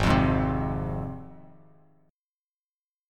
Ab6 Chord
Listen to Ab6 strummed